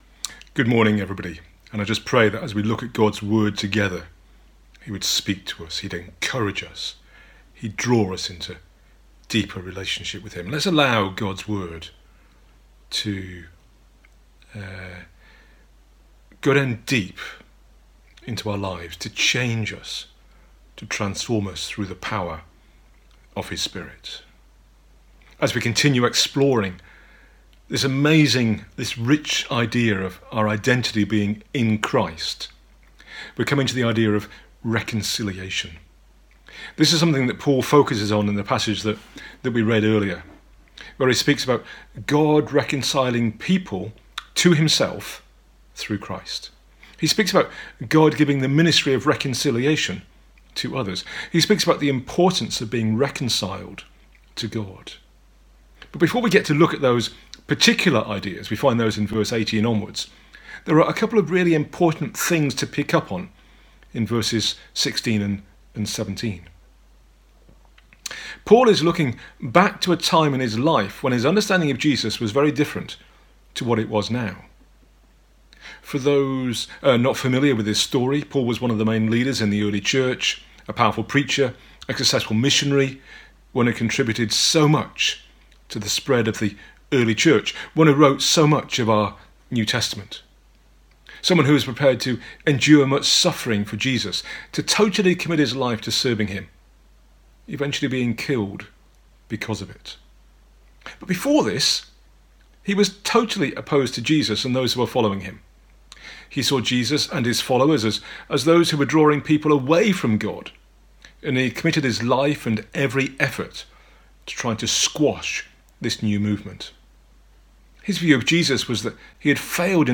A talk from the series "Identity in Christ."